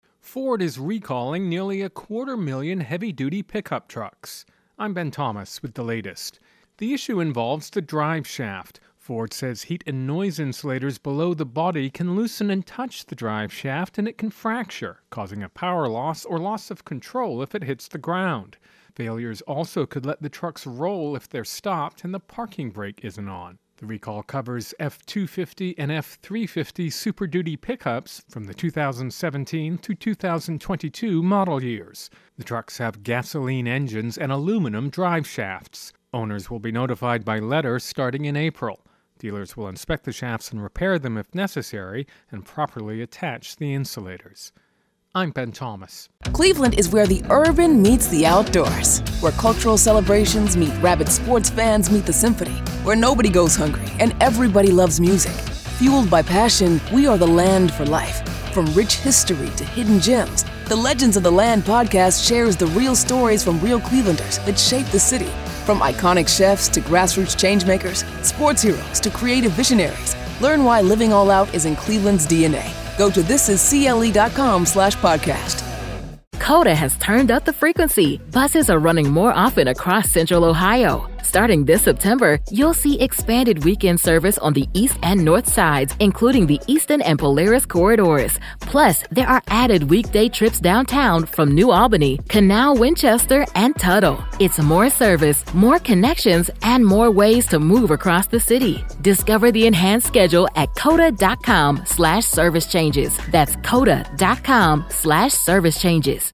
Intro and voicer "Ford Pickup Recall"